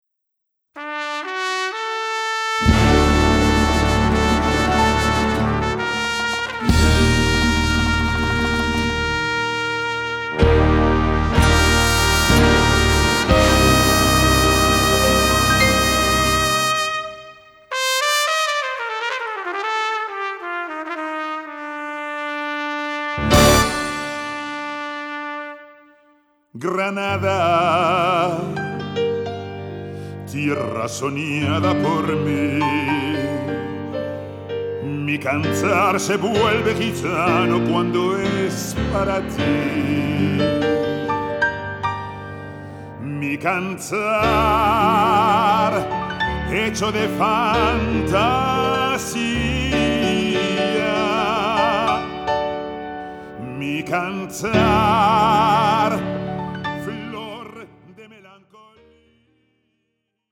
Salsa-Klänge